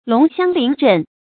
龍驤麟振 注音： ㄌㄨㄙˊ ㄒㄧㄤ ㄌㄧㄣˊ ㄓㄣˋ 讀音讀法： 意思解釋： 龍驤，昂舉騰躍貌。